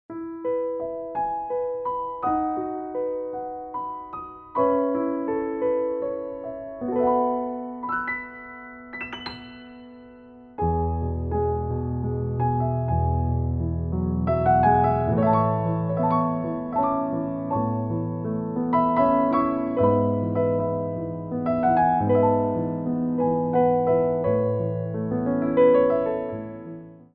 Adagio
3/4 (8x8)